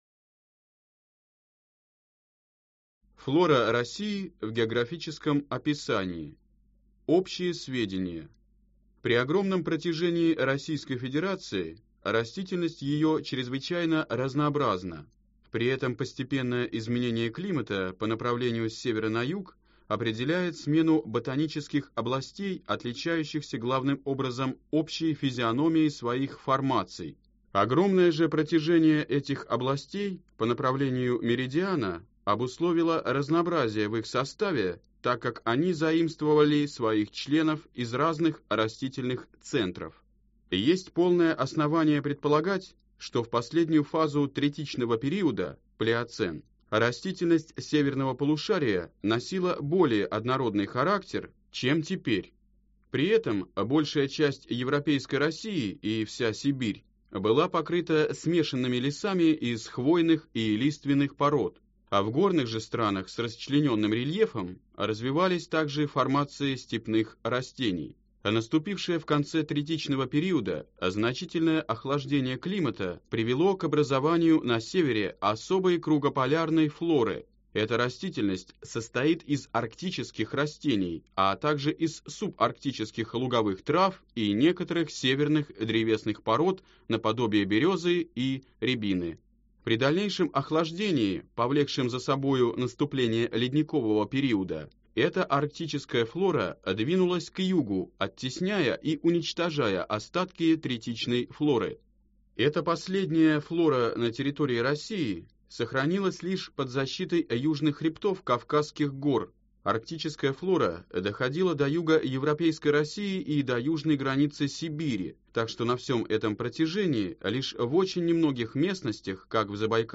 Аудиокнига Растительный мир России | Библиотека аудиокниг